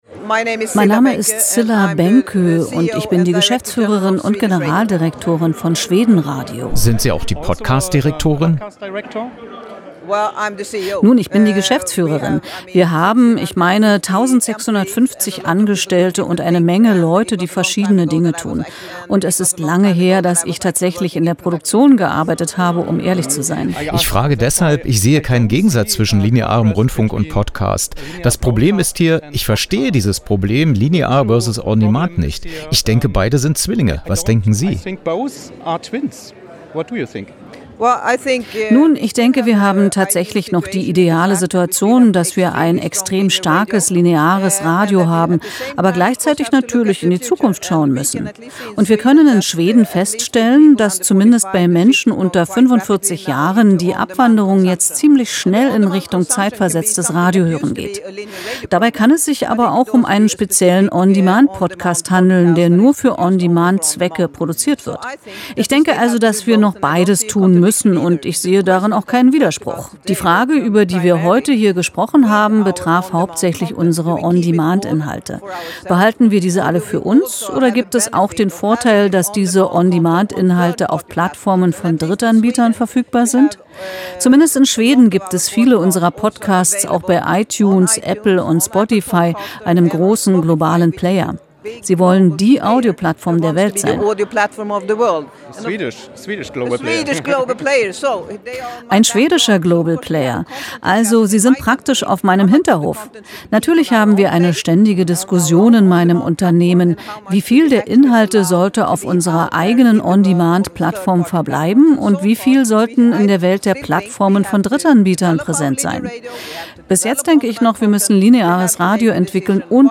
* Cilla Benkö, CEO/Generaldirektorin von Sveriges Radio
Was: Interview über die Prix-Europa-Diskussion: „The Big Debate: Linear vs. On-demand – Radio and Streaming Platforms: Friends or Enemies?“
Wo: Potsdam, Schiffbauergasse, Prix Europa